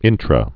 (ĭntrə)